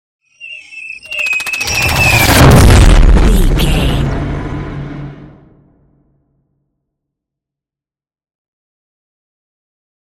Scifi passby whoosh long
Sound Effects
Atonal
futuristic
intense
pass by